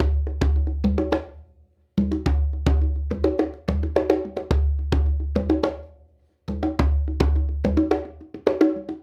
Djembe and Conga 02.wav